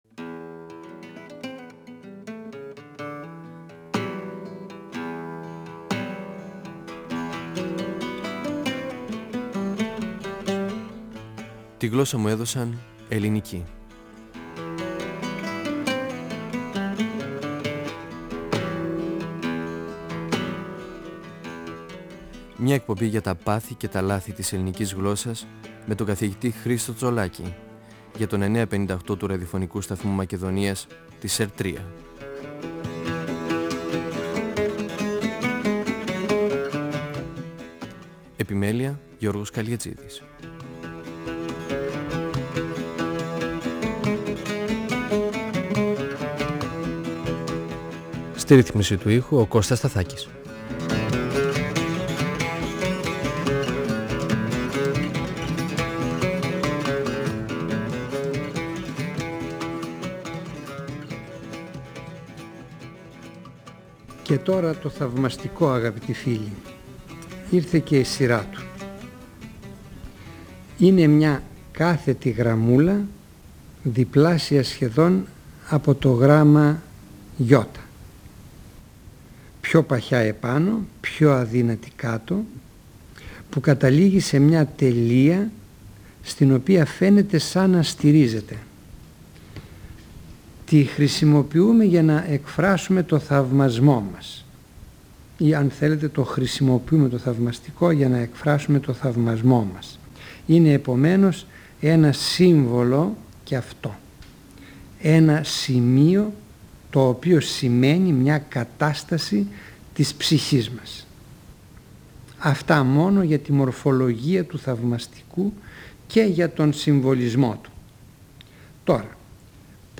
Ο γλωσσολόγος Χρίστος Τσολάκης (1935-2012) μιλά για τα σημεία στίξης και για το θαυμαστικό και πότε το χρησιμοποιούμε (επιφώνημα, επιφωνηματική φράση ή έκφραση, για να τονίσουμε κάτι ξαφνικό, αιφνίδιο, αναπάντεχο, ανόητο, απίστευτο).
Νησίδες & 9.58fm, 1999 (πρώτος, δεύτερος, τρίτος τόμος), 2006 (τέταρτος τόμος, πέμπτος τόμος). 958FM Αρχειο Φωνες Τη γλωσσα μου εδωσαν ελληνικη "Φωνές" από το Ραδιοφωνικό Αρχείο Εκπομπές ΕΡΤ3